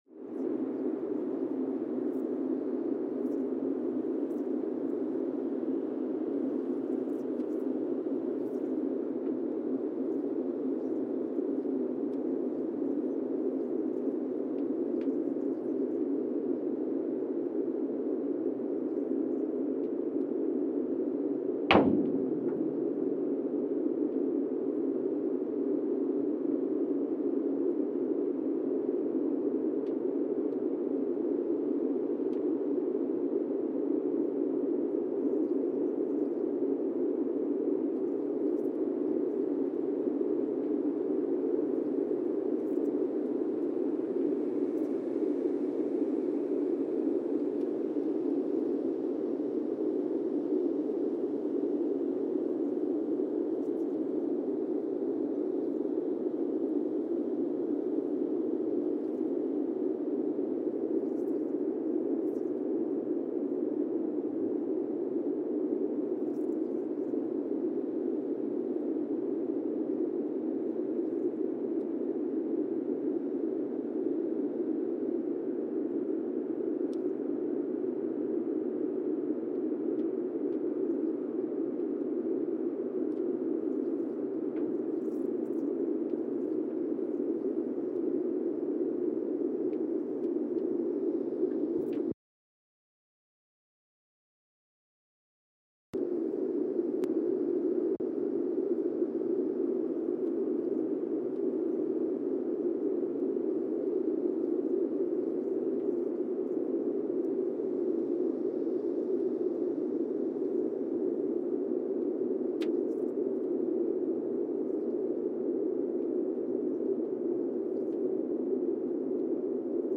Monasavu, Fiji (seismic) archived on February 6, 2024
Sensor : Teledyne Geotech KS-54000 borehole 3 component system
Speedup : ×1,800 (transposed up about 11 octaves)
SoX post-processing : highpass -2 90 highpass -2 90